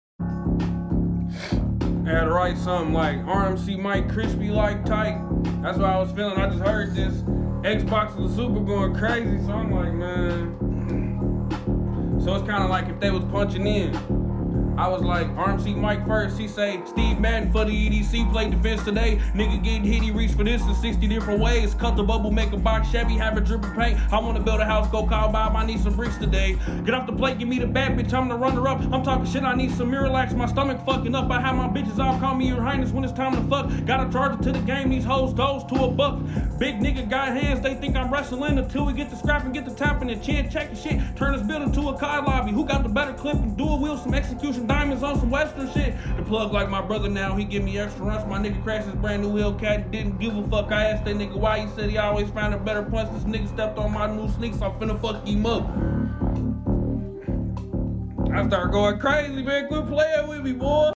Started Talking Crazy 😂 Shout Sound Effects Free Download